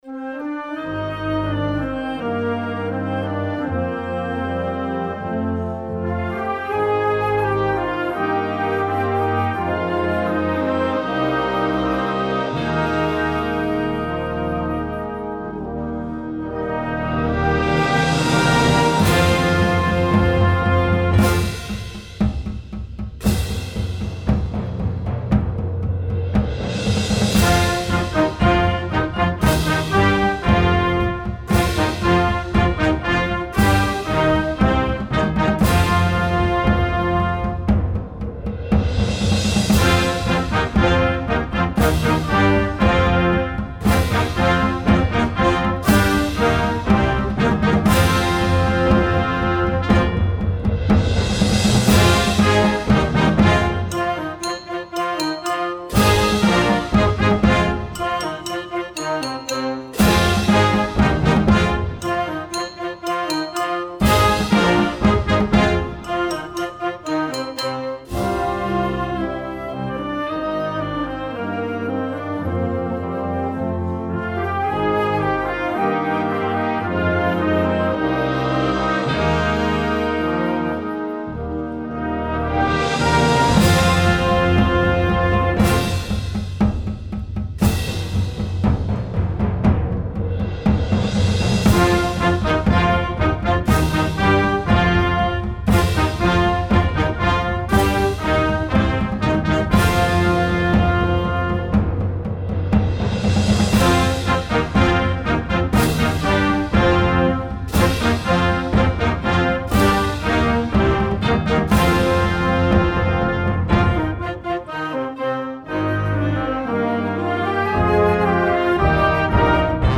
Partitions pour orchestre d'harmonie.
Real music, using only the first octave!